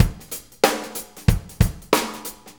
Shuffle Loop 28-07.wav